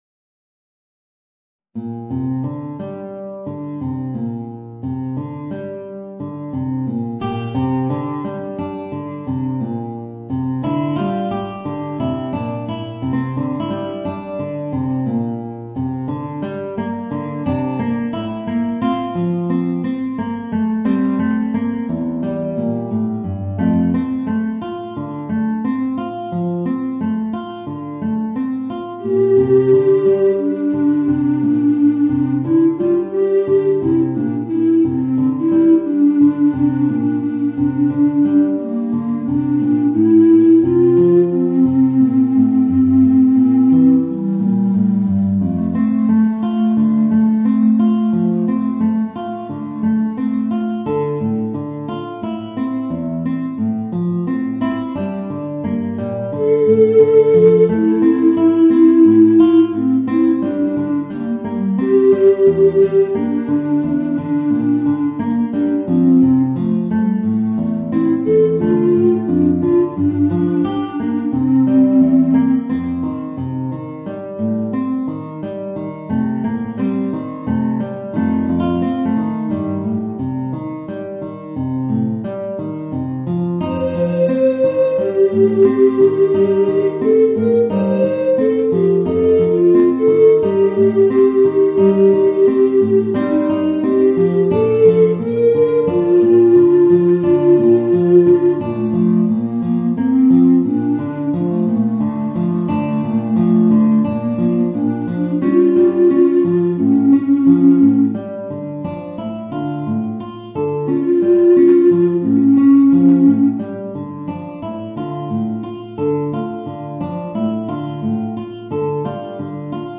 Voicing: Bass Recorder and Guitar